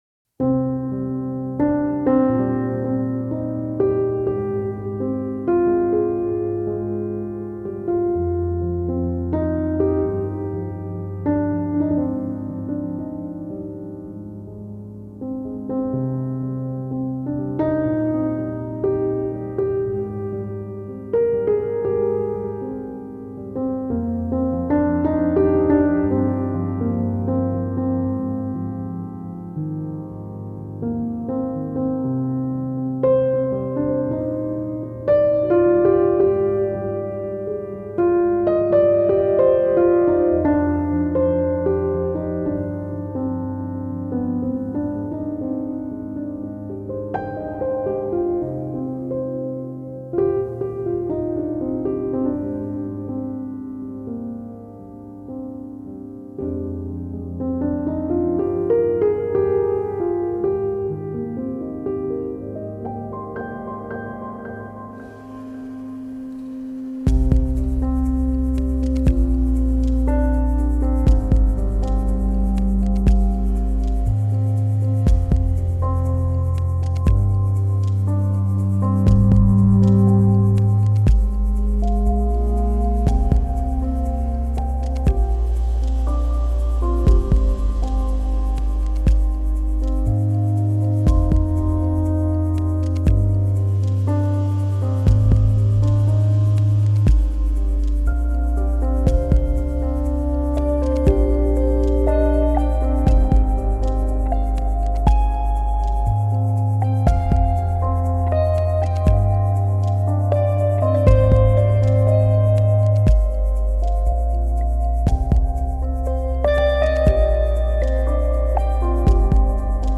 Emotional and thoughtful piano theme with ticking elements.